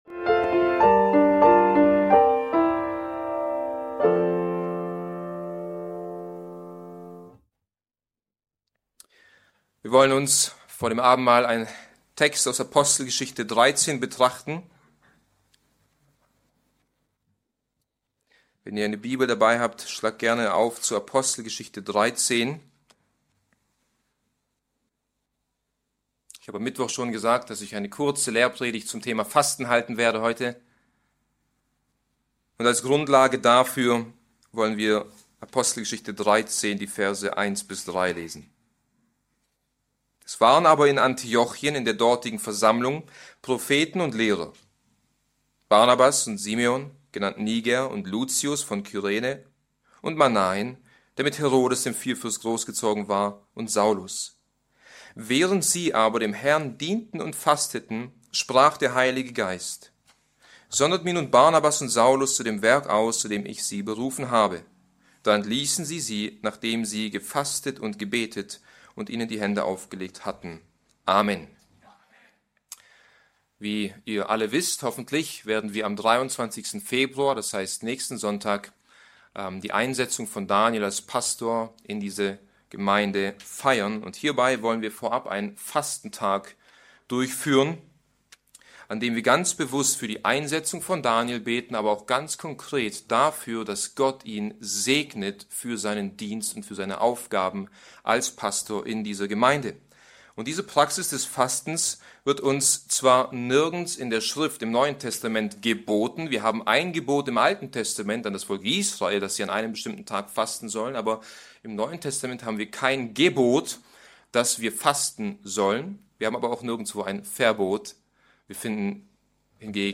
Bibeltreue Predigten der Evangelisch-Baptistischen Christusgemeinde Podcast